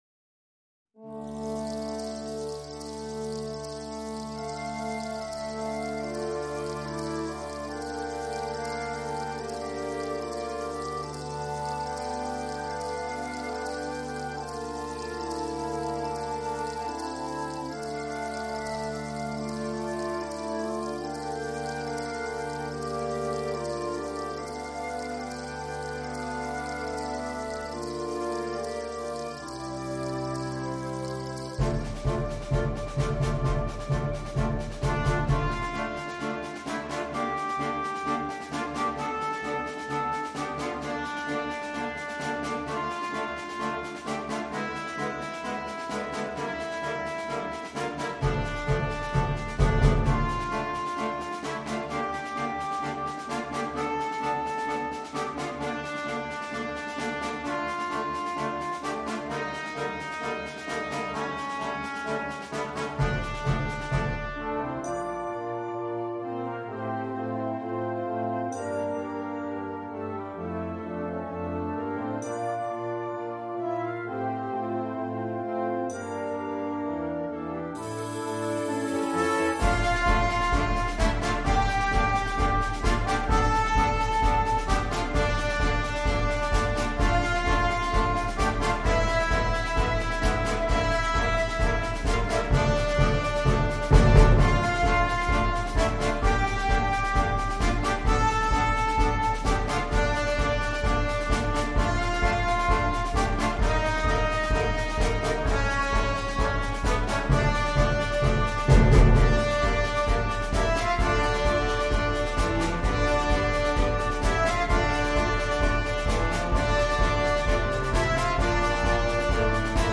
fantasia per bada